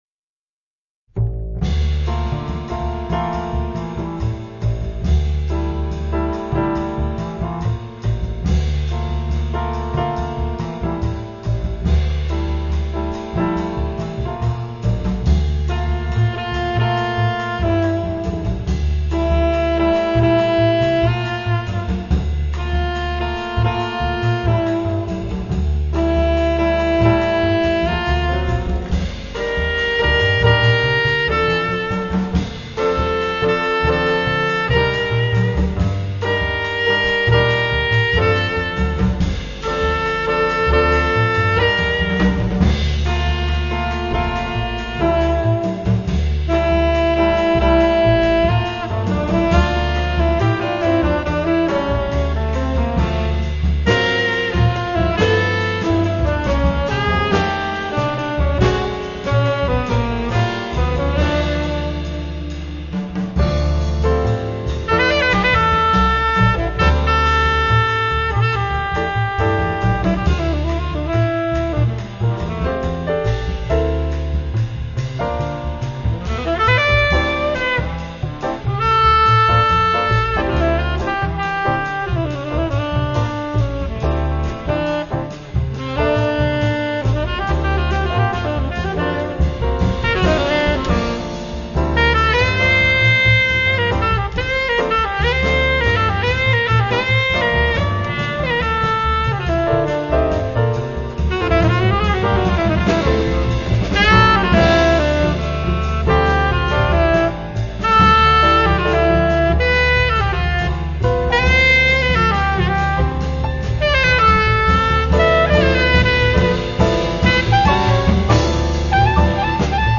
sax tenore e soprano
frutto di una registrazione live
Trasognato e malinconico